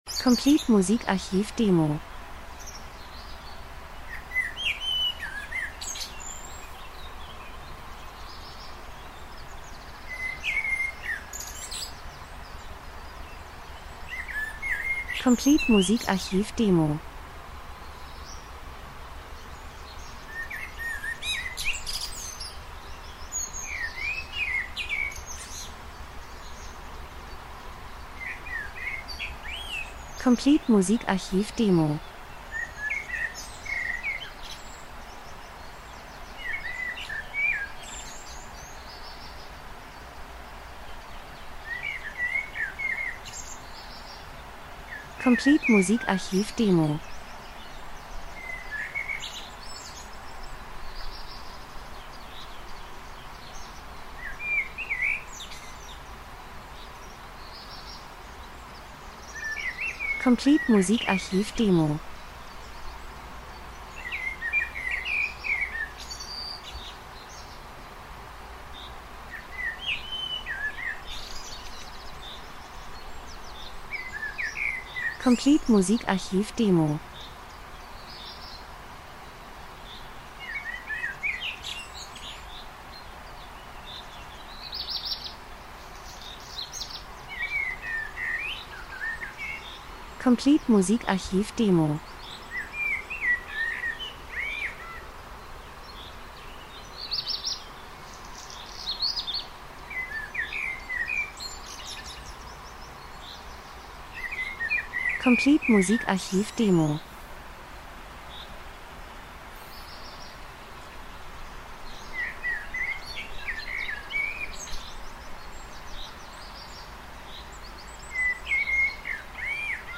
Frühling -Geräusche Soundeffekt Natur Vögel Wind Garten 03:32